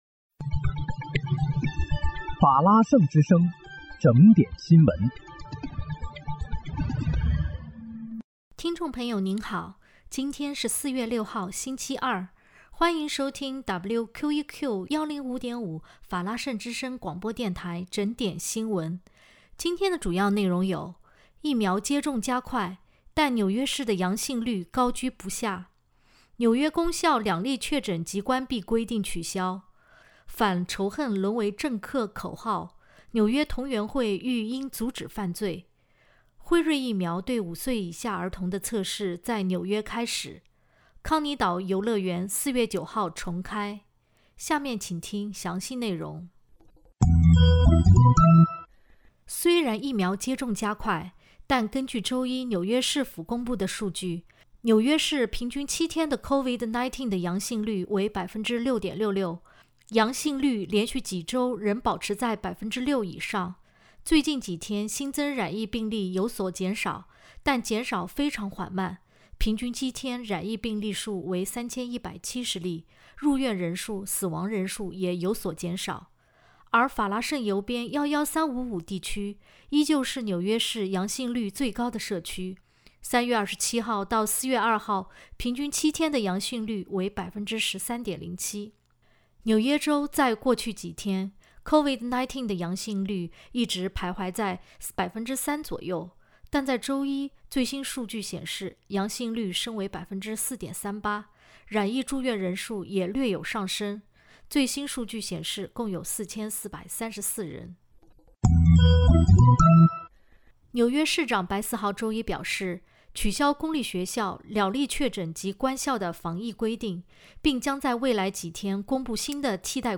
4月6日（星期二）纽约整点新闻